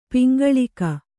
♪ piŋgaḷika